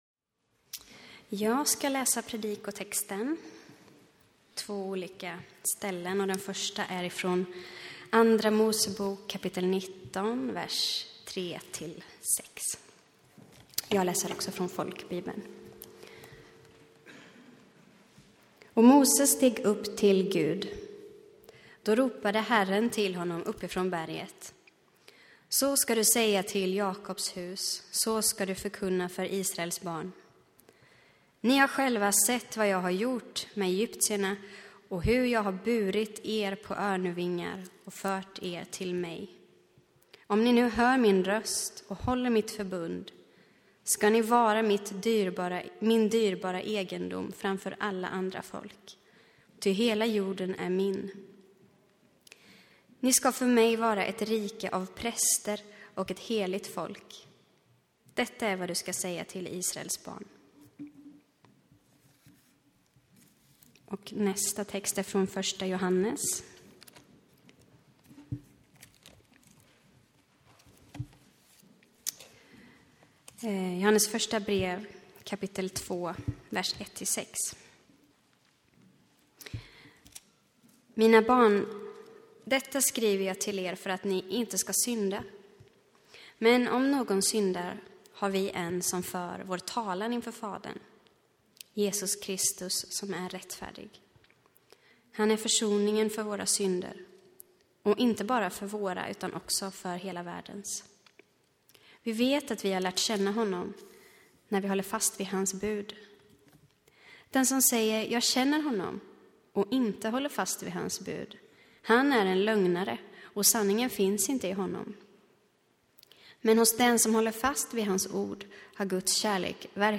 Inspelad i Baptistkyrkan Tabernaklet i Göteborg 2014-01-05.